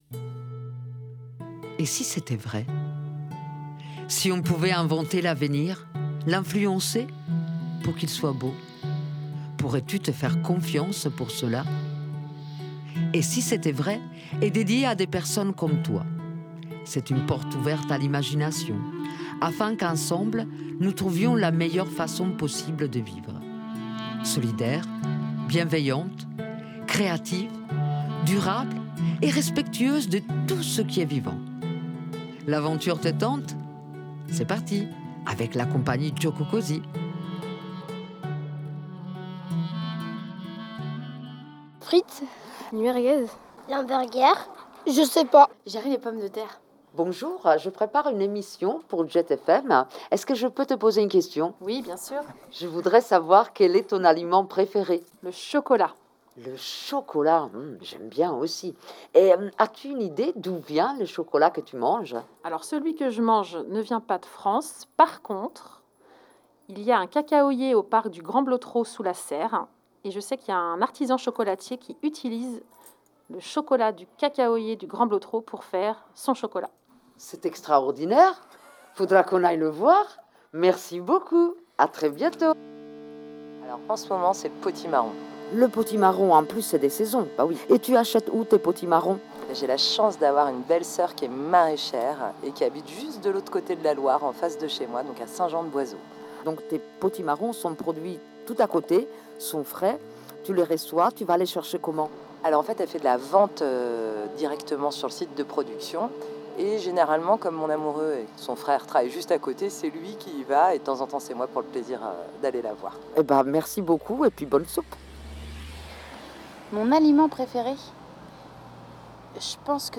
Et si c’était vrai ? par Et si c’était vrai Aujourd’hui je suis partie dans le quartier de Bellevue, pour savoir quel est l’aliment préféré des personnes qui y habitent, y travaillent et/ou y passent par hasard.